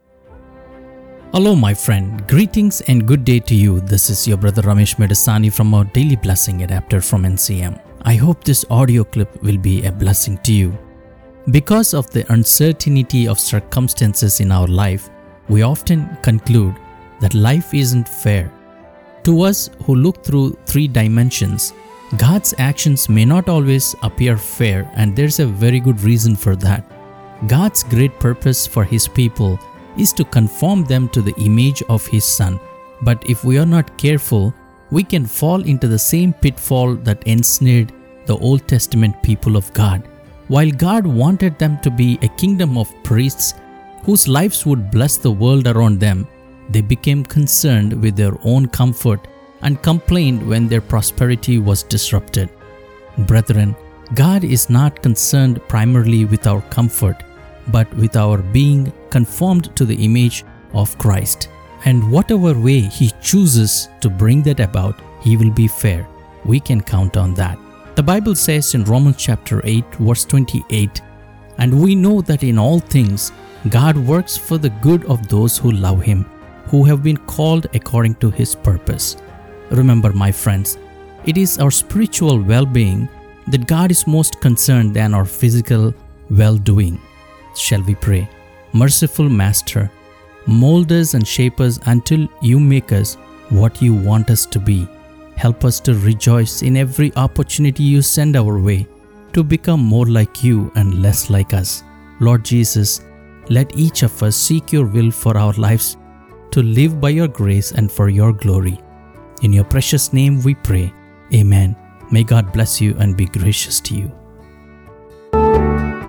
Our Daily Blessing is a 2 minute Discipleship based Daily Devotion that is aimed at presenting the Gospel in a way that is quick to listen and slow to meditate…